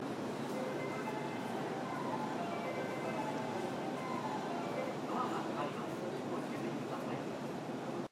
発車メロディー途中切りです。